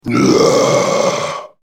دانلود آهنگ دعوا 42 از افکت صوتی انسان و موجودات زنده
دانلود صدای دعوا 42 از ساعد نیوز با لینک مستقیم و کیفیت بالا
جلوه های صوتی